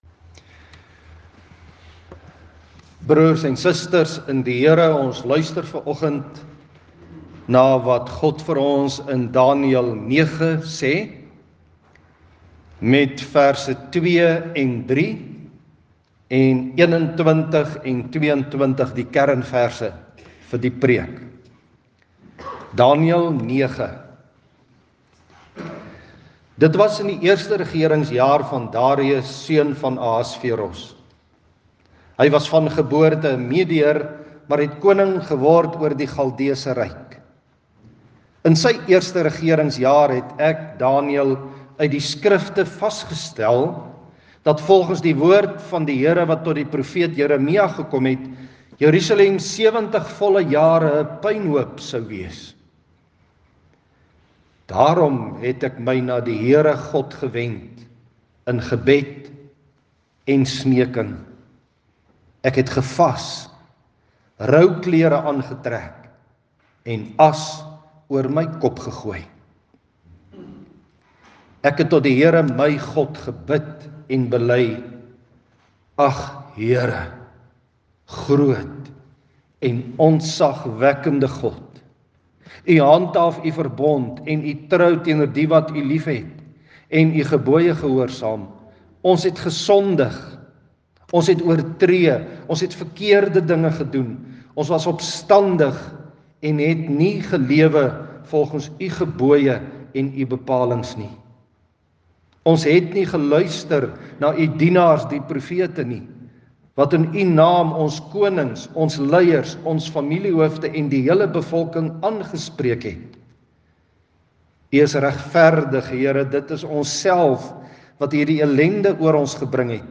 Vanoggend se preek oor Daniël 9 verduidelik die hoofstuk in twee woorde: Verootmoediging en verlossing.